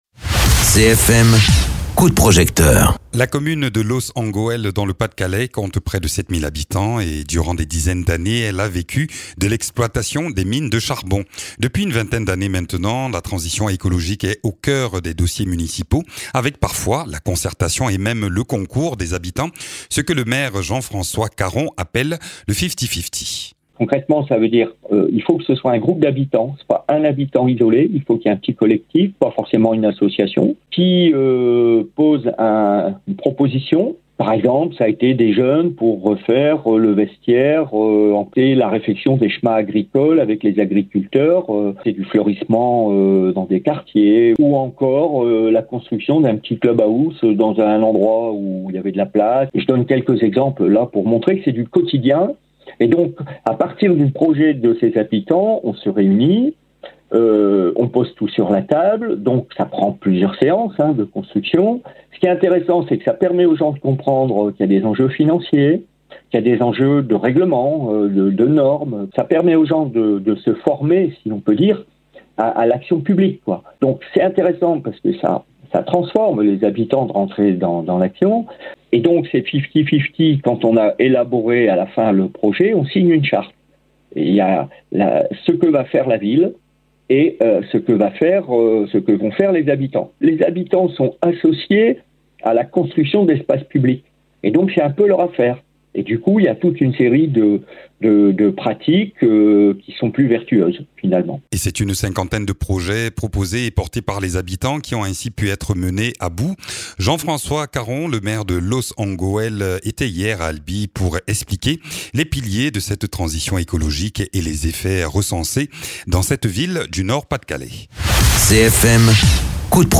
Interviews
Invité(s) : Jean-François Caron, maire de Loos-en-Gohelle.